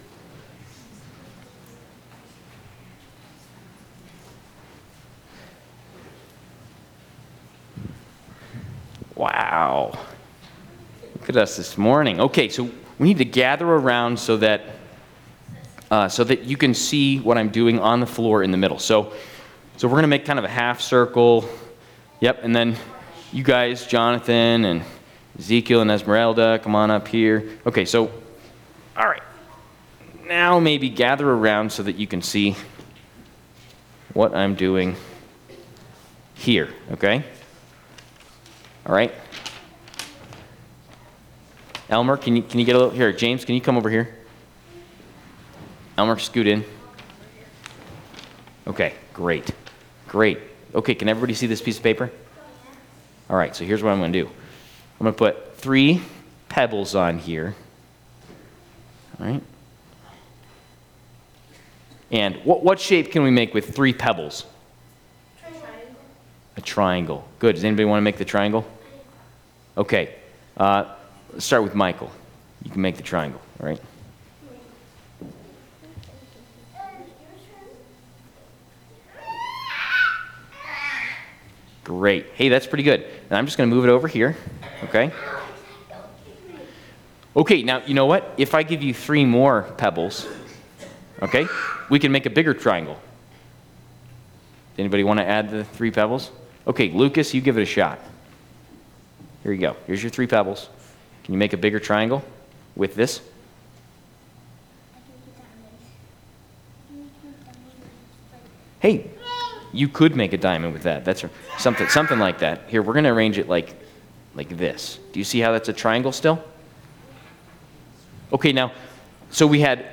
Combined Childrens' Sermon and Adult Sermon&nbsp